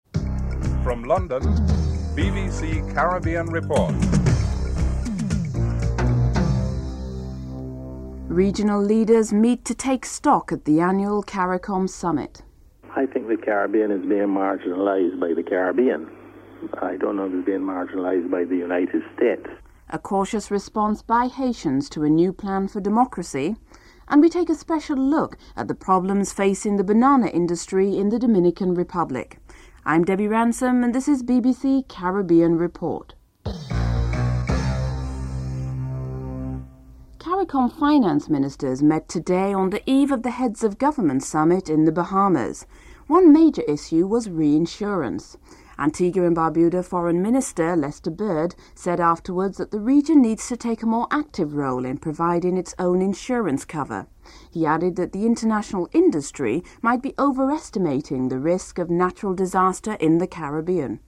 Interview with Erskvine Sandiford, Prime Minister of Barbados.